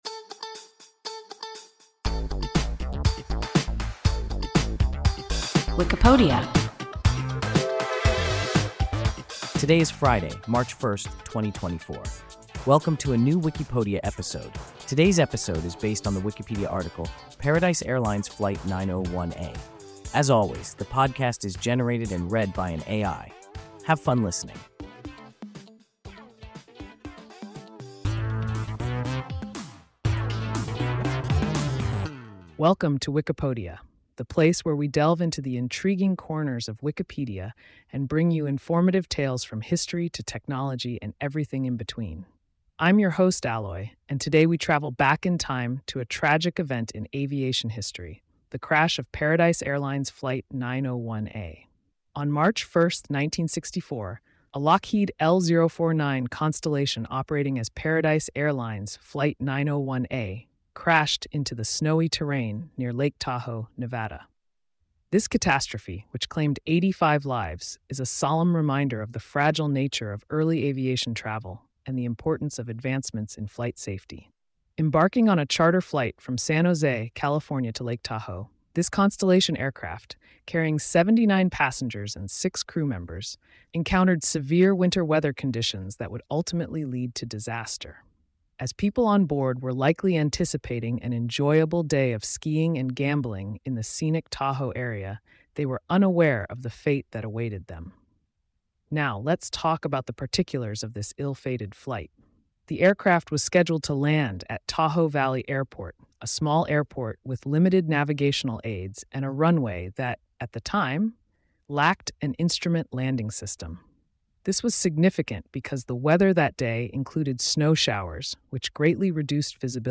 Paradise Airlines Flight 901A – WIKIPODIA – ein KI Podcast